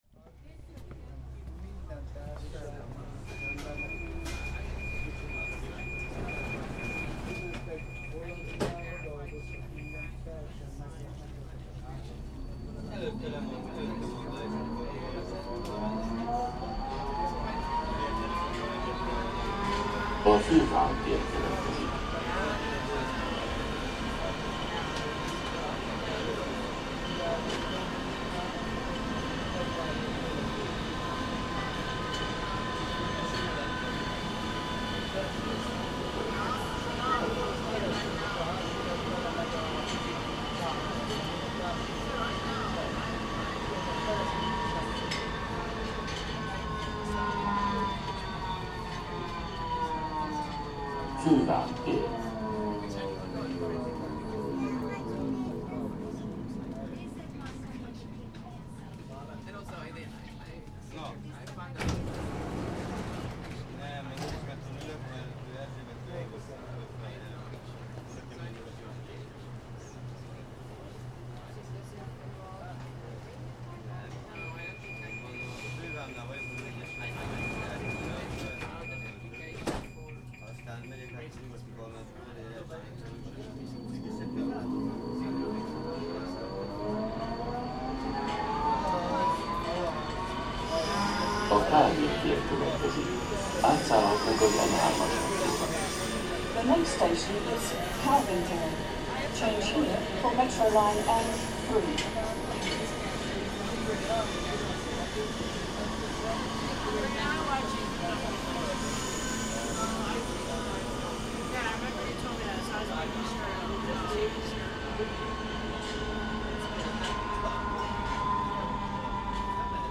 A ride on the clean, modern and efficient Budapest metro to Rakoczi ter stop.
Recorded in Budapest, Hungary by Cities and Memory.